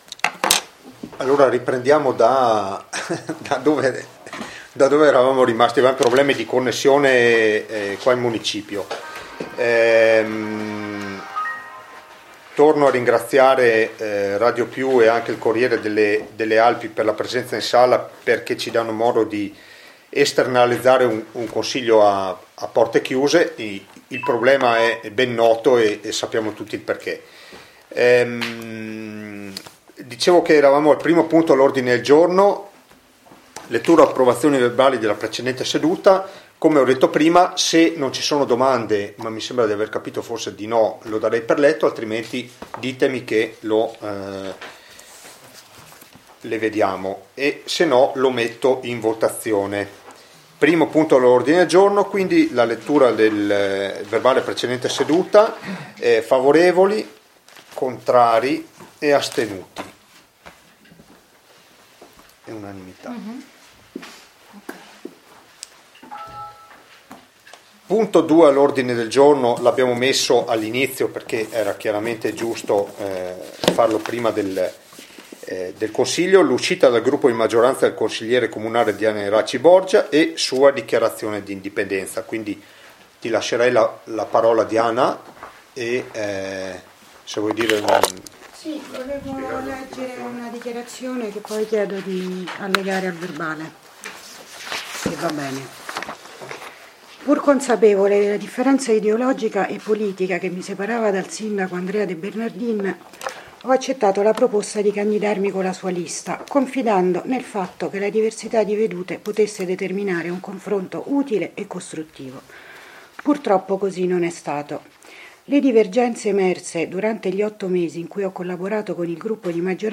IL CONSIGLIO COMUNALE DI LUNEDI SERA A ROCCA PIETORE, REGISTRAZIONE INTEGRALE